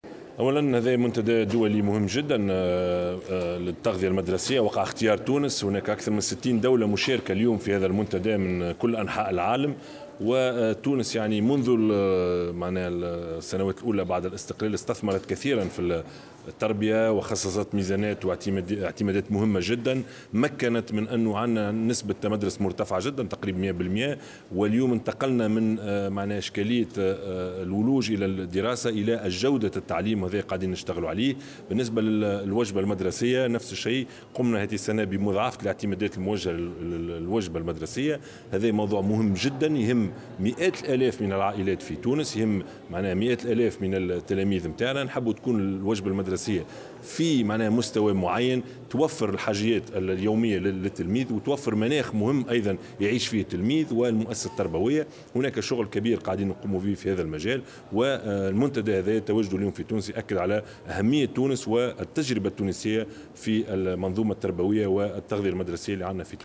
واعتبر انعقاد المؤتمر الدولي لتغذية الطفل في تونس مهما جدا حيث تشارك فيه اكثر من 60 دولة كما يؤكد على أهمية التجربة التونسية في المنظومة التربويّة. وأكد في تصريح لمراسل "الجوهرة اف أم" أن حكومته تشتغل على جودة التعليم وان الوجبة المدرسية بالمؤسسات التربوية تهمّ مئات الآلاف من العائلات التونسية، وفق تعبيره.